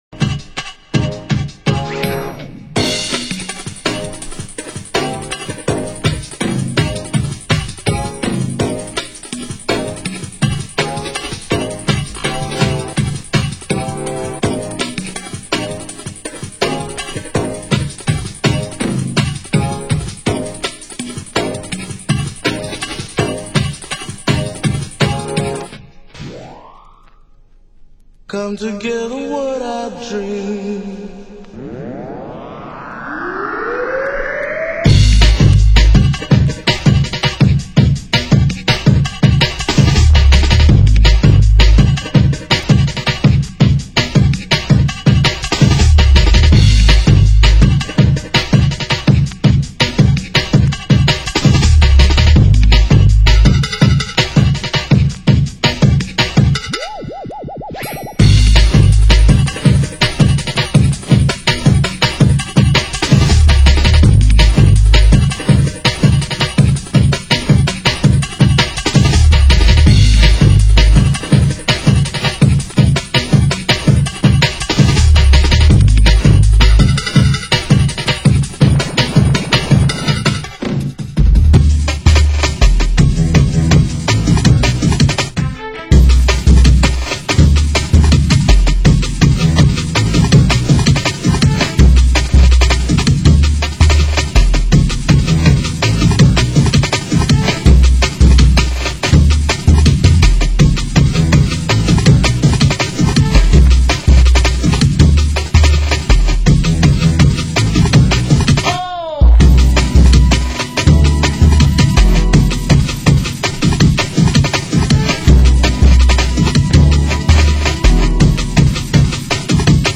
Genre Jungle